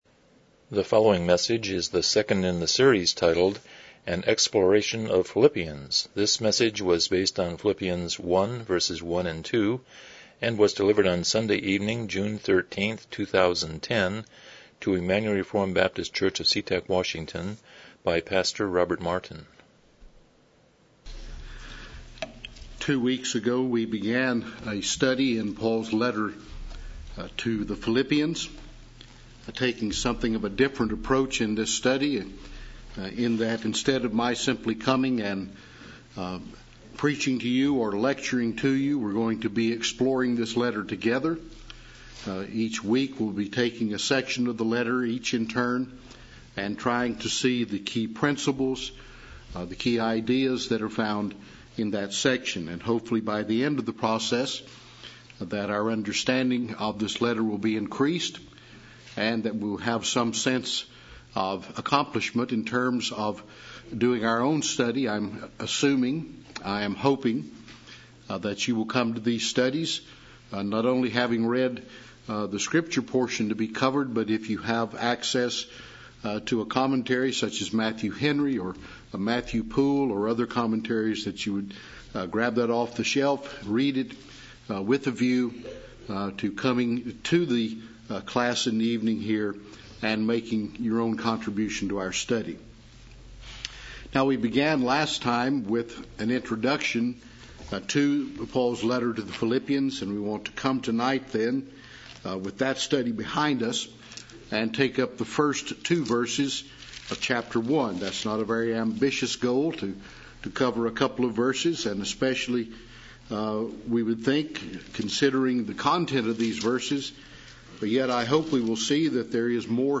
Philippians 1:1-2 Service Type: Evening Worship « 113 Romans 8:28-30 #8 100 Chapter 19.3